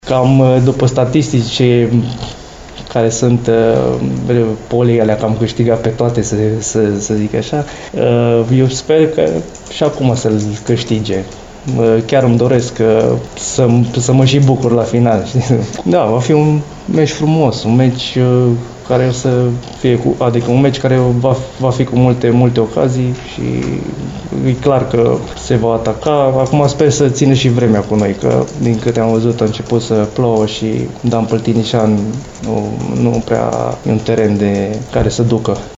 la conferința de presă de astăzi